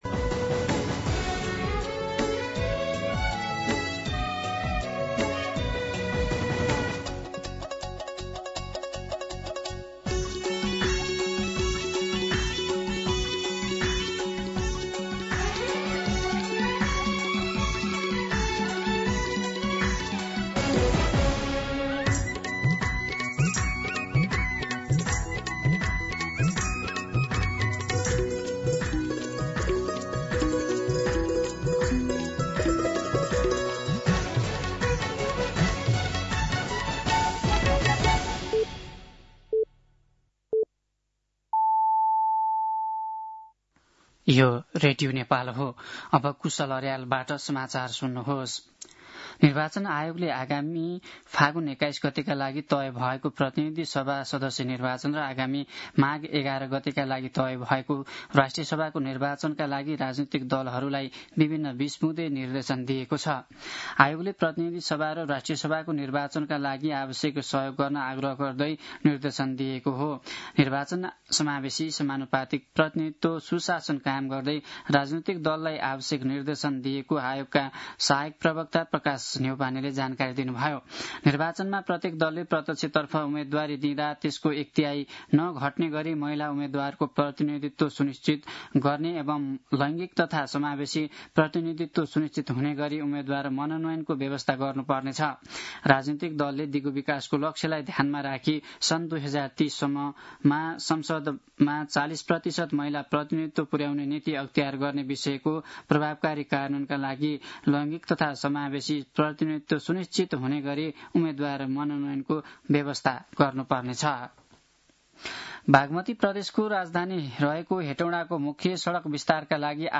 मध्यान्ह १२ बजेको नेपाली समाचार : २० मंसिर , २०८२
12-pm-Nepali-News.mp3